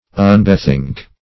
Search Result for " unbethink" : The Collaborative International Dictionary of English v.0.48: Unbethink \Un`be*think"\, v. t. [1st pref. un- + bethink.] To change the mind of (one's self).